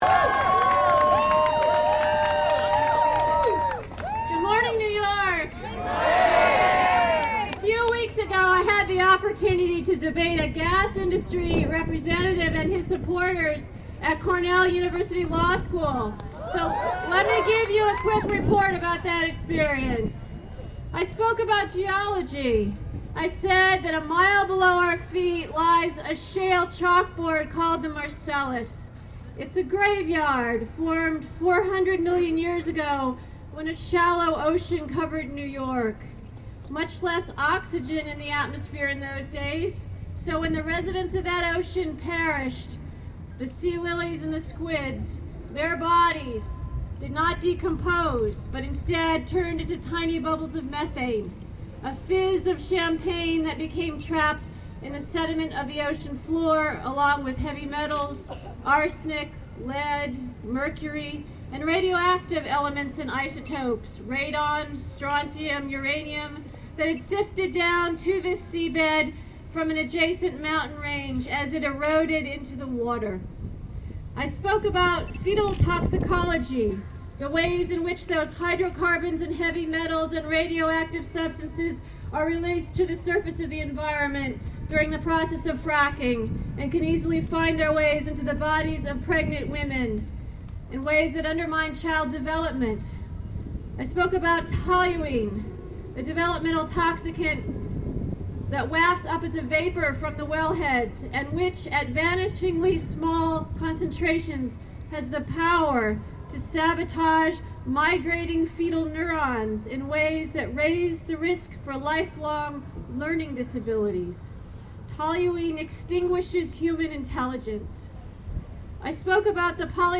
Dr. Sandra Steingraber, speaking at Albany Earth Day rally May 2, about hydraulic fracturing drilling for natural gas.